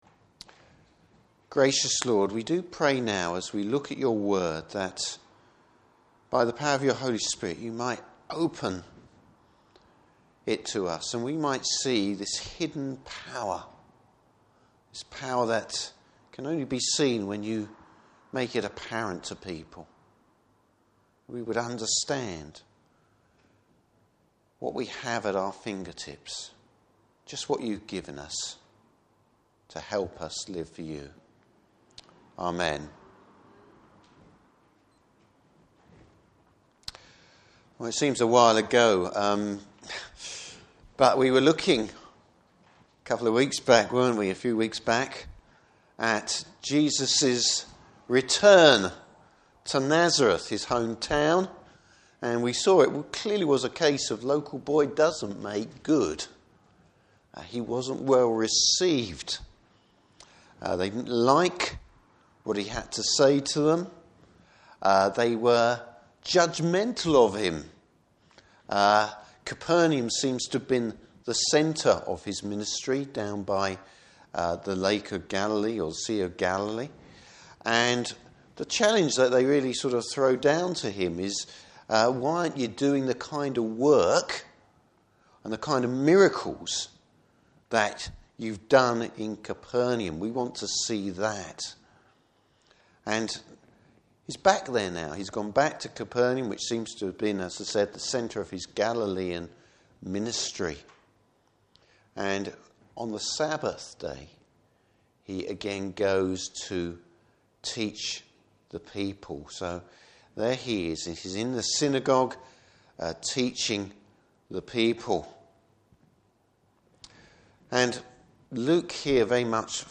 Service Type: Morning Service Bible Text: Luke 4:31-44.